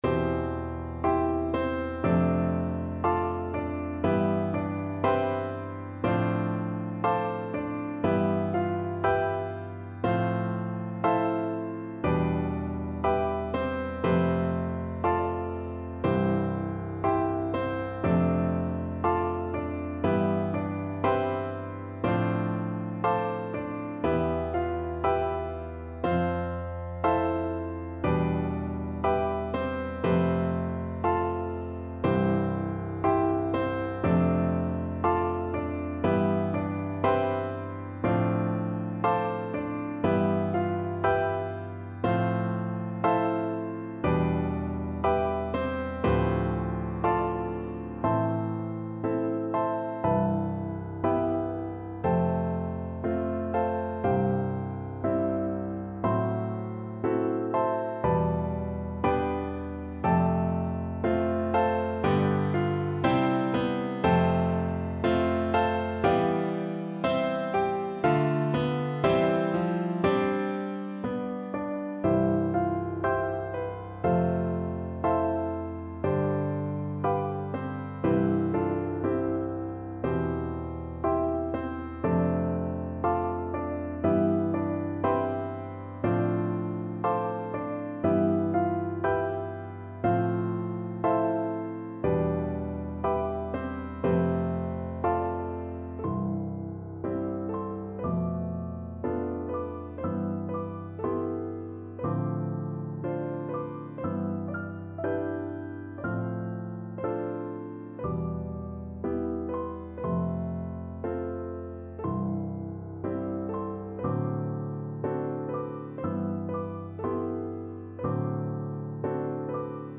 Flute
C major (Sounding Pitch) (View more C major Music for Flute )
4/4 (View more 4/4 Music)
Moderato con moto =60
G5-C7
Classical (View more Classical Flute Music)